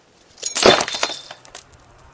破碎的玻璃 " 破碎的玻璃1
描述：一个原始音频mp3录制的人扔在外面的水泥玻璃和它粉碎。包括一些风的背景噪音。用黑色Sony IC录音机录制。